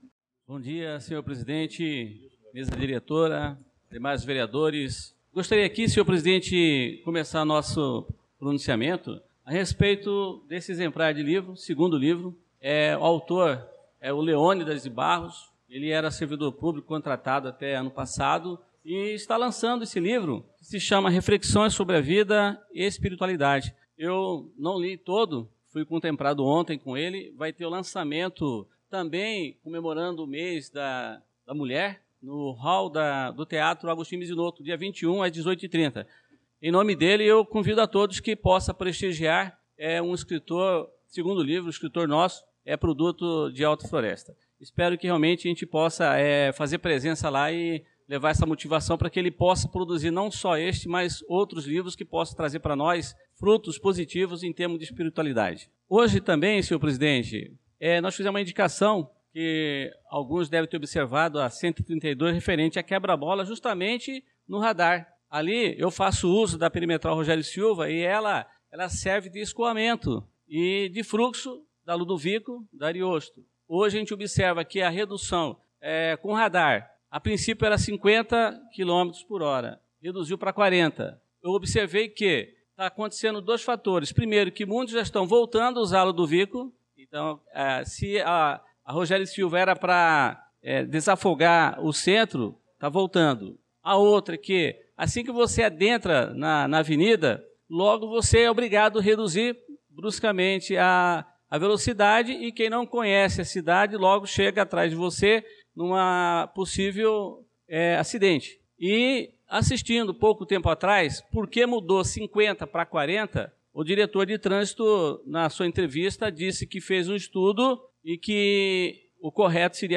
Pronunciamento do vereador Adelson Servidor na Sessão Ordinária do dia 11/03/2025